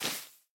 sounds / block / moss / step1.ogg
step1.ogg